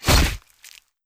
Melee Weapon Attack 26.wav